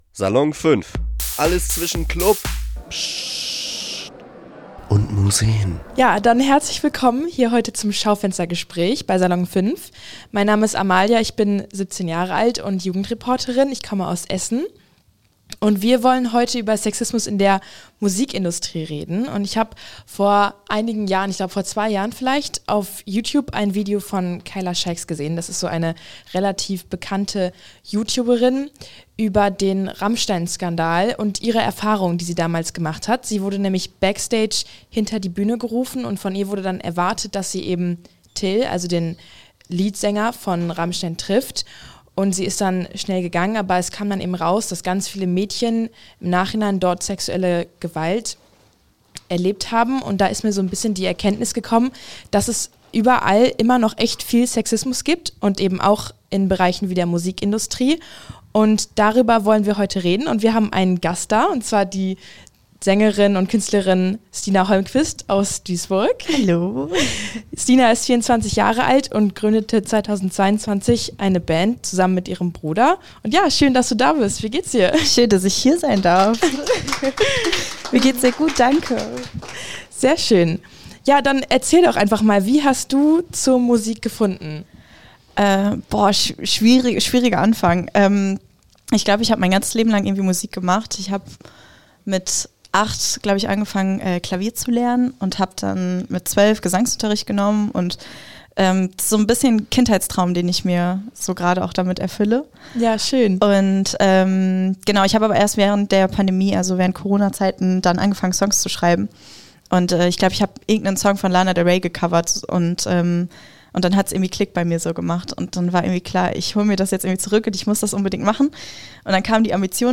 Dieses Gespräch ist eine Aufzeichnung vom Schaufenstergespräch am 27. März 2026.